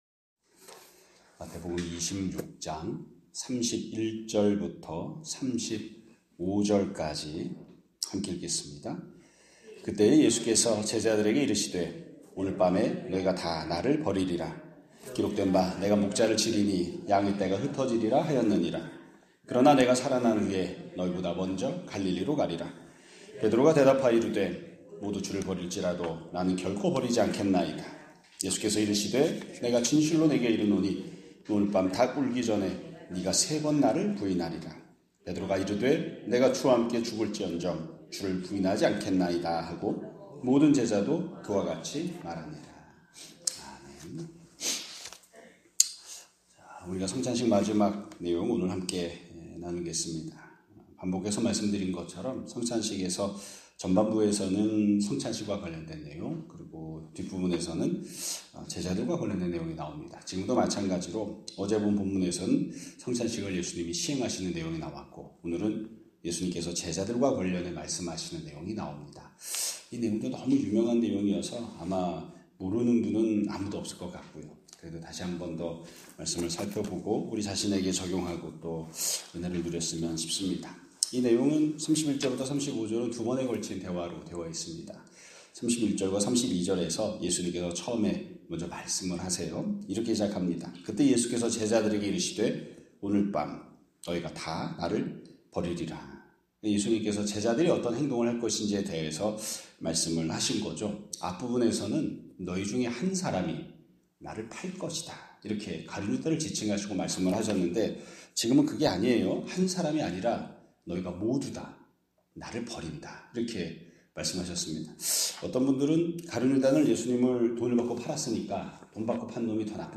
2026년 4월 2일 (목요일) <아침예배> 설교입니다.